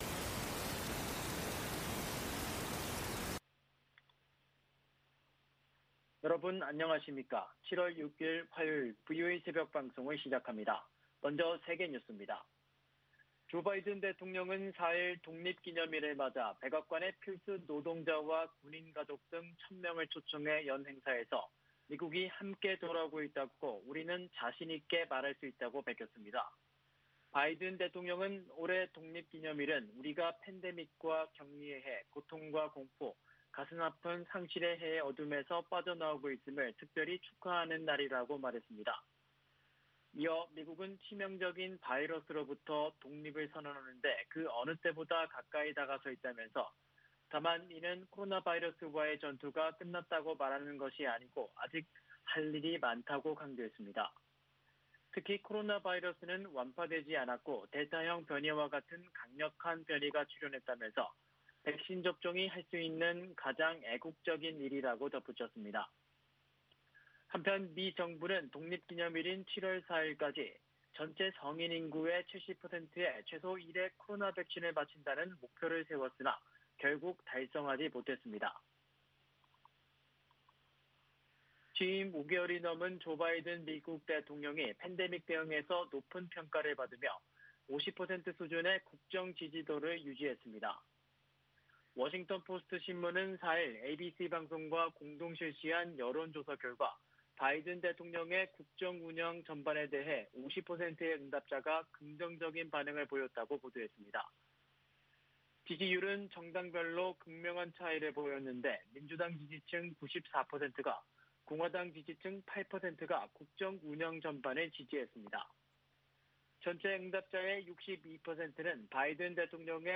세계 뉴스와 함께 미국의 모든 것을 소개하는 '생방송 여기는 워싱턴입니다', 아침 방송입니다.